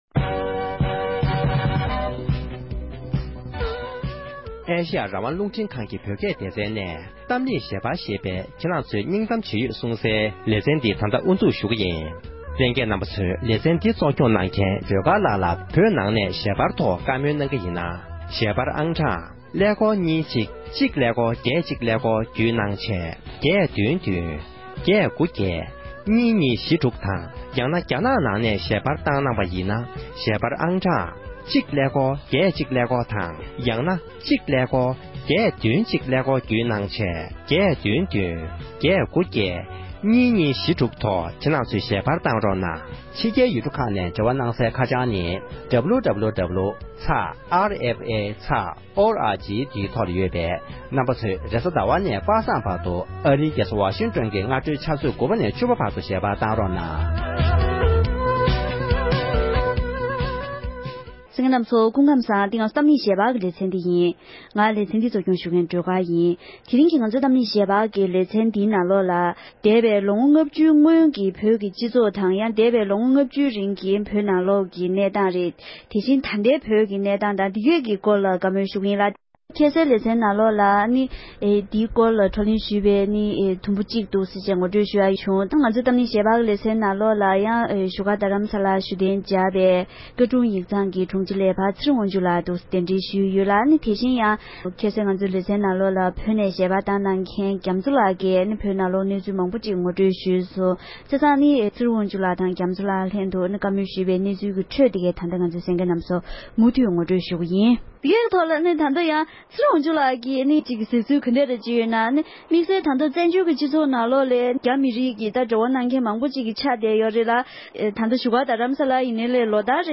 ༄༅༎དེ་རིང་གི་གཏམ་གླེང་ཞལ་པར་གྱི་ལེ་ཚན་ནང་དུ་འདས་པའི་ལོ་ངོ་ལྔ་བཅུའི་སྔོན་གྱི་བོད་ཀྱི་སྤྱི་ཚོགས་དང་ལམ་ལུགས་དེ་བཞིན་ལོ་ངོ་ལྔ་བཅུའི་རྗེ་ཀྱི་ད་ལྟའི་བོད་ཀྱི་སྤྱི་ཚོགས་དང་ལམ་ལུགས་ལ་དཔྱད་ཞིབ་ཞུ་བའི་ལེ་ཚན་གྱི་དུམ་མཚམས་གཉིས་པར་གསན་རོགས་ཞུ༎